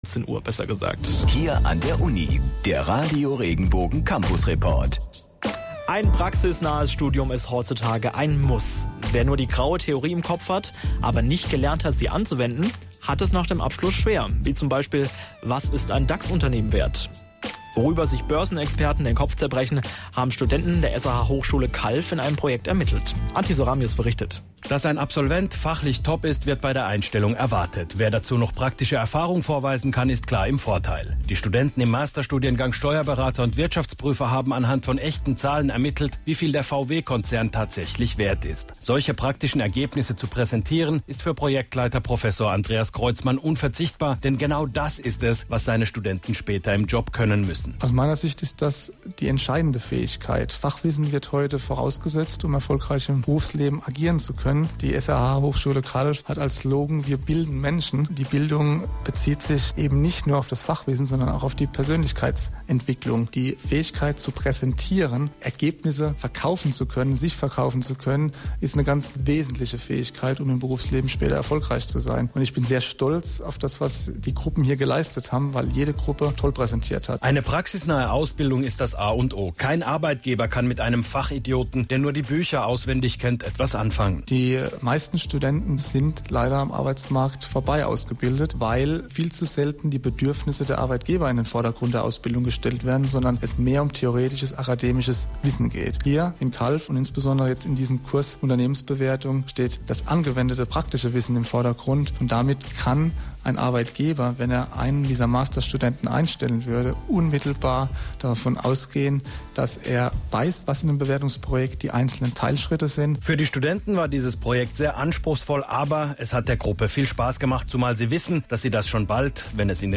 SRH Campus Report: Praxisschock vermeiden – Master Studenten der SRH Hochschule Calw bewerten den VW Konzern. Radio Regenbogen berichtet hierüber im Campus Report.